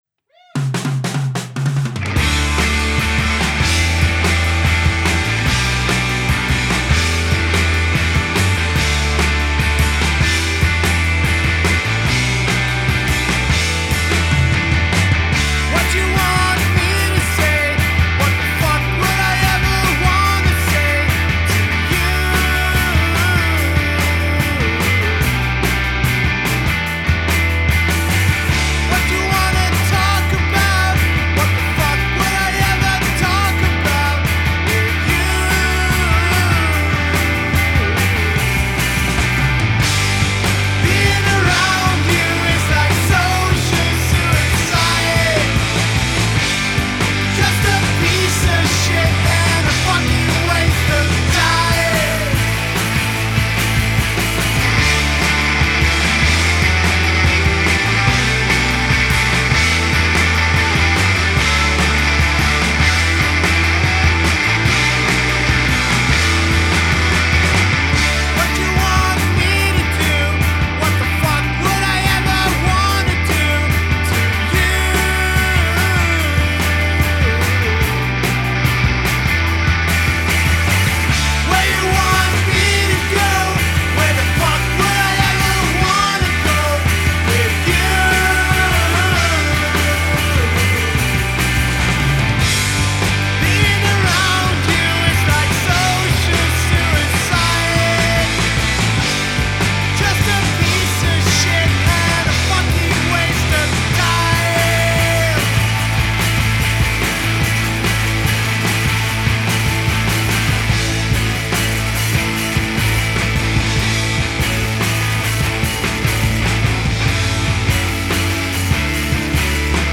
Swedish indie throwbacks